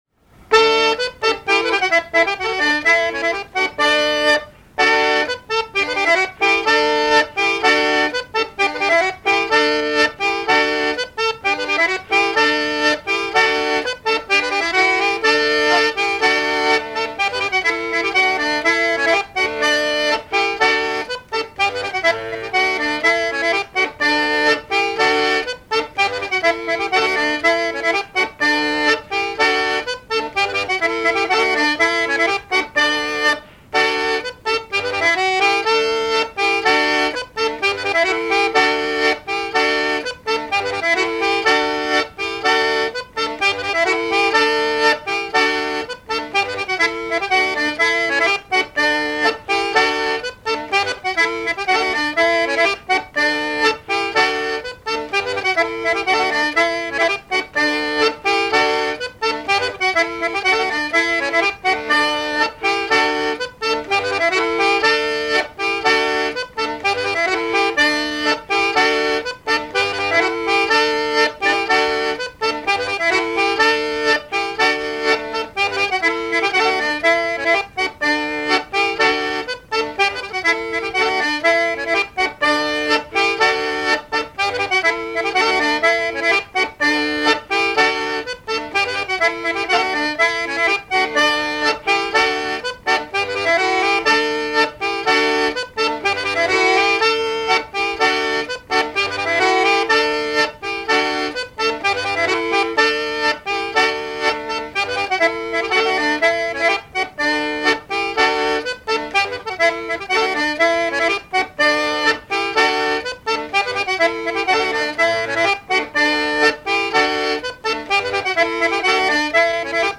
danse : ronde à la mode de l'Epine
Répertoire d'airs à danser
Pièce musicale inédite